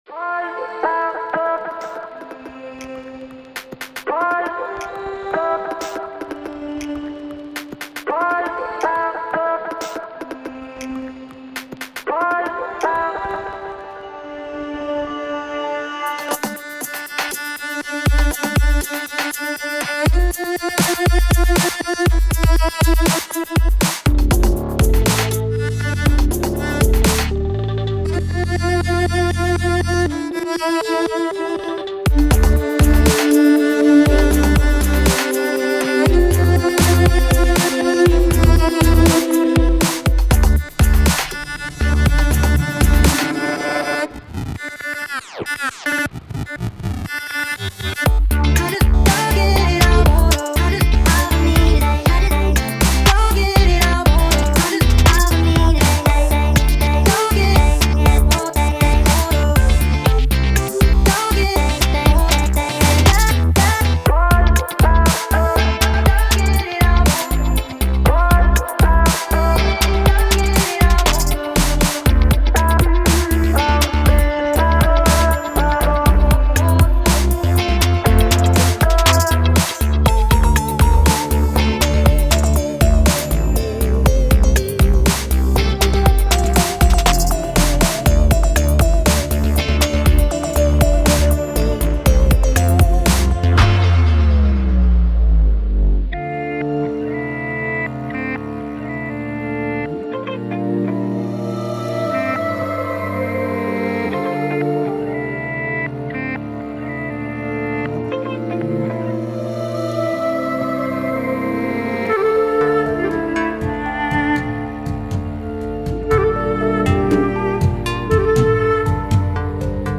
Представляю вашему вниманию демку.
Пока что ничего не сводилось и не мастерилось. Пространство тоже не прорабатывалось, чисто на скорую руку.